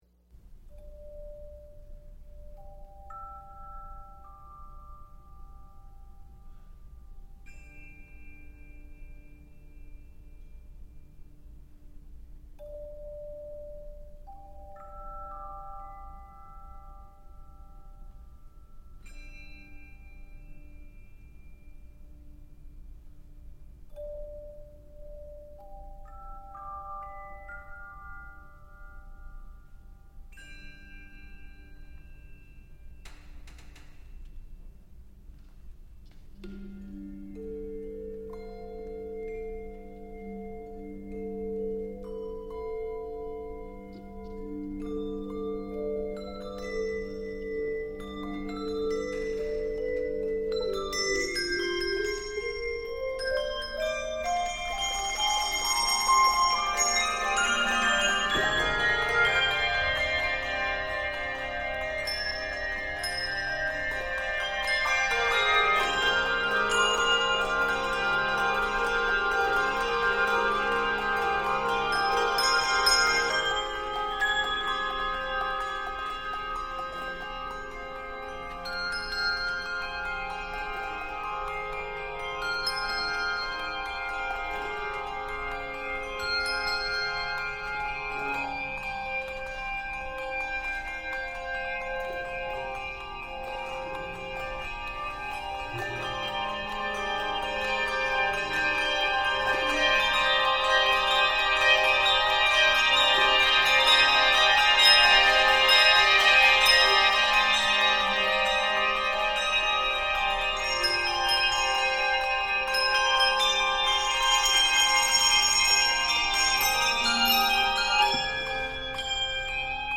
N/A Octaves: 5-6 Level